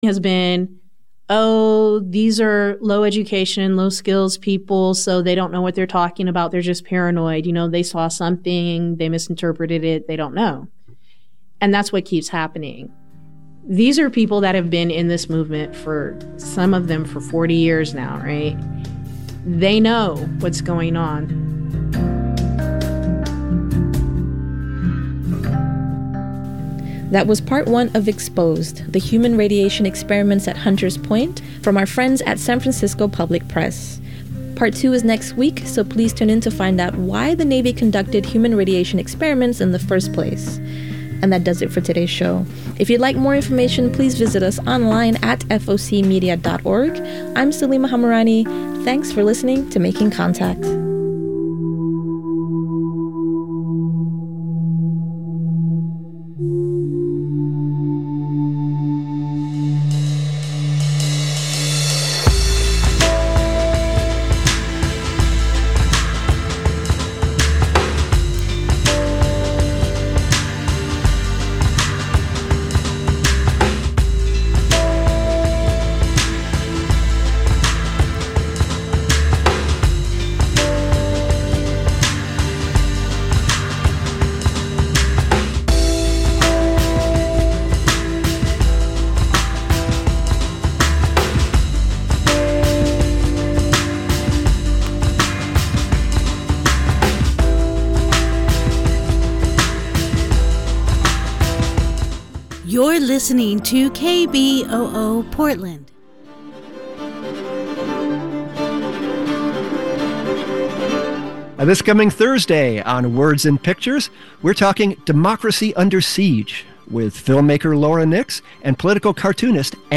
Labor Radio